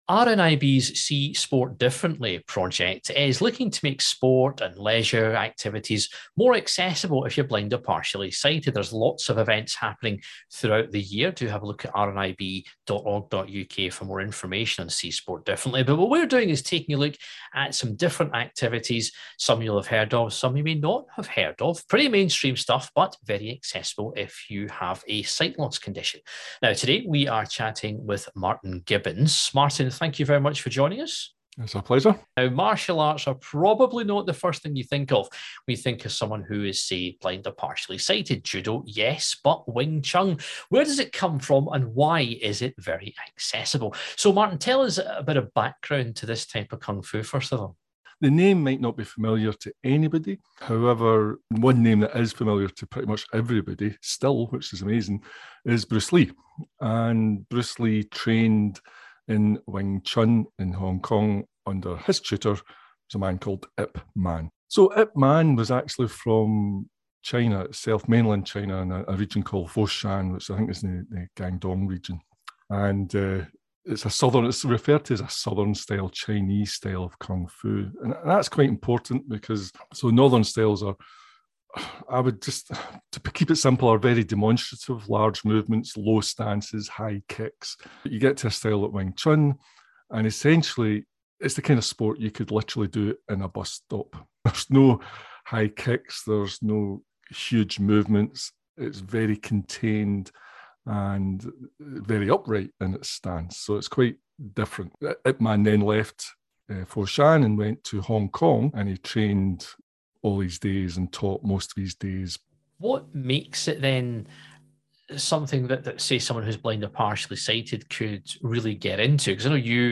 Wing Chun teacher